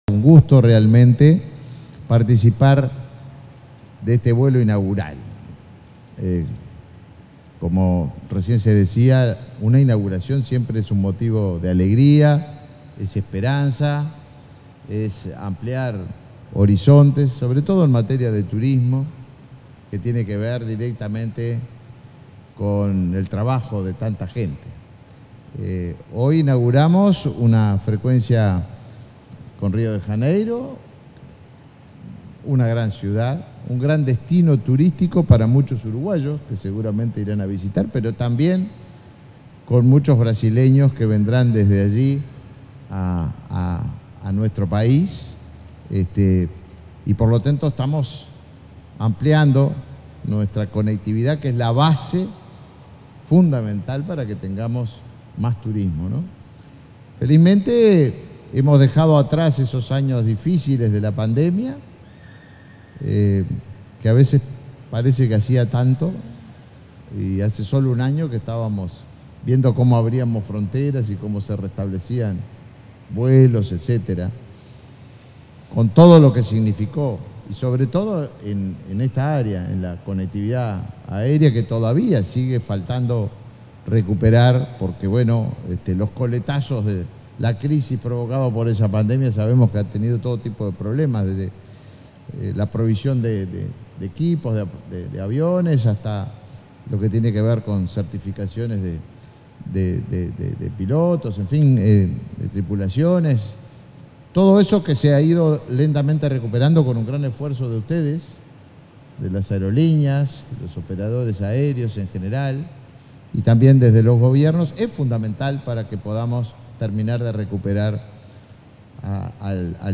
Palabras del ministro de Turismo, Tabaré Viera 28/03/2023 Compartir Facebook X Copiar enlace WhatsApp LinkedIn En el marco del vuelo inaugural entre Montevideo y Río de Janeiro de la empresa JetSmart, este 28 de marzo, se expresó el ministro de Turismo, Tabaré Viera.